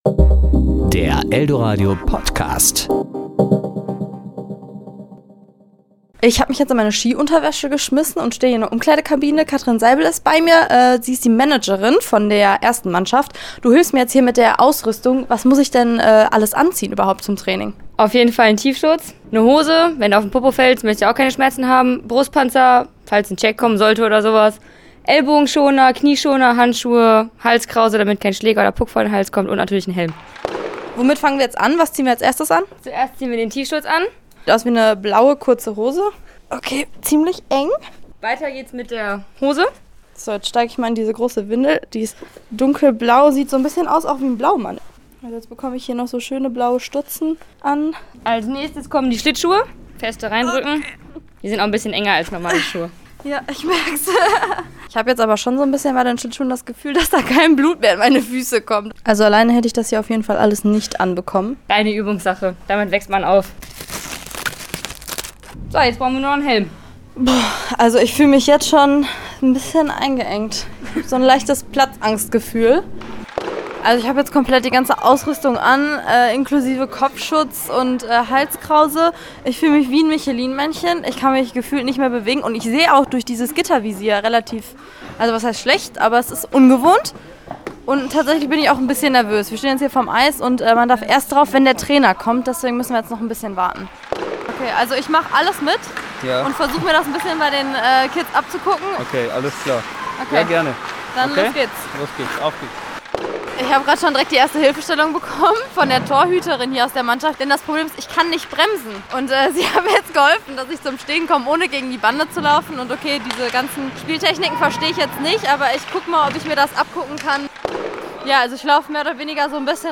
Probetraining bei den Eishockey-Bambinis